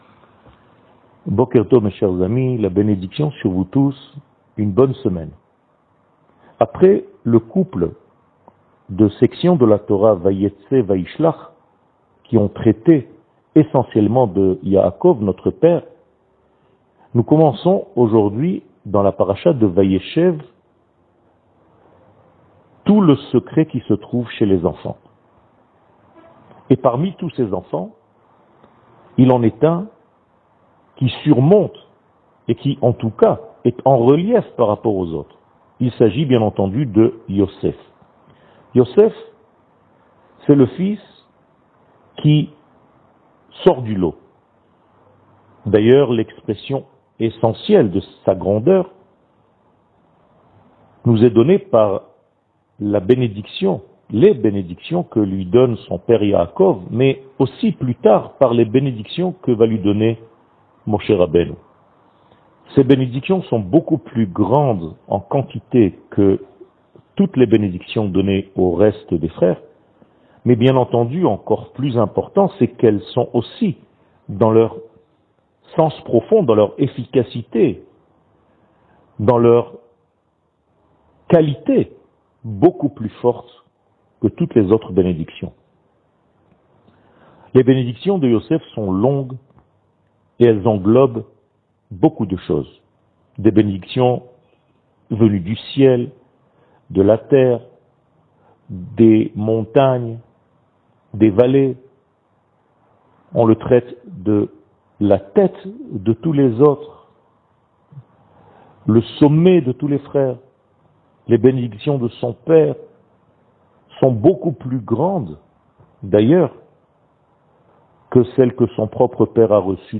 שיעור מ 21 נובמבר 2021
שיעורים קצרים